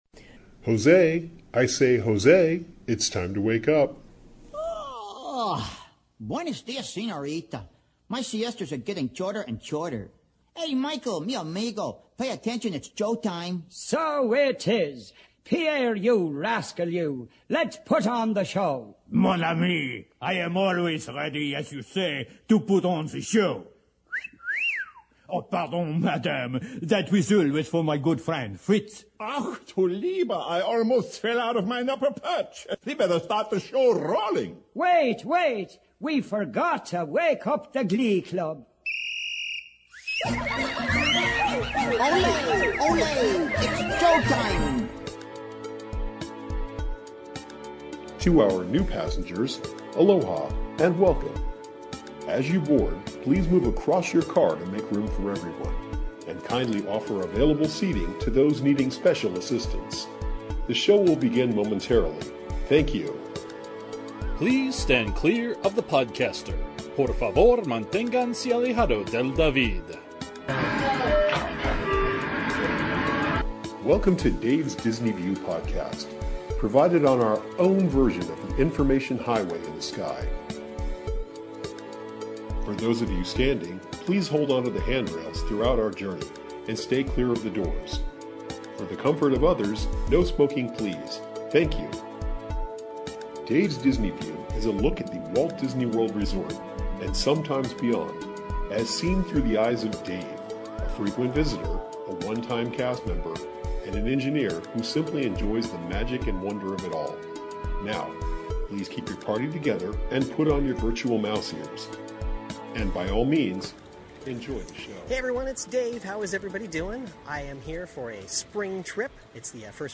Come along as I go into Epcot, and explore parts of Future World, and present some attraction audio.